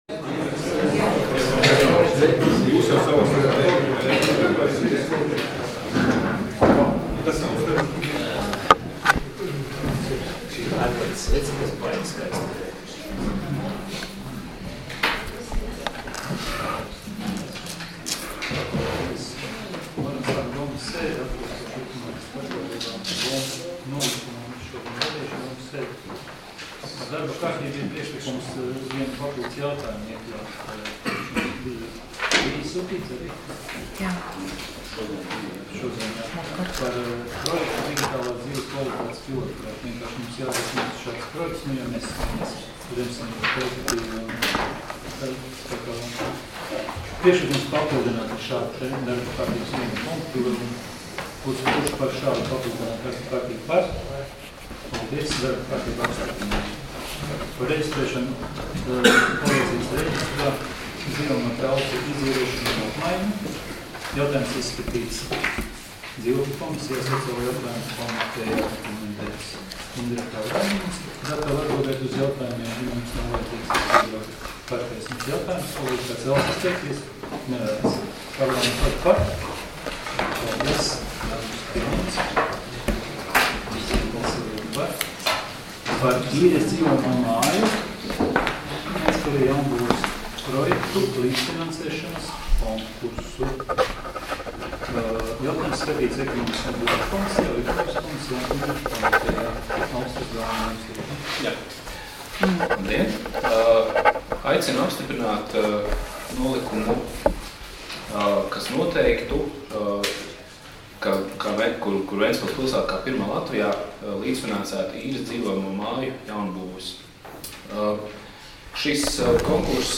Domes sēdes 06.12.2019. audioieraksts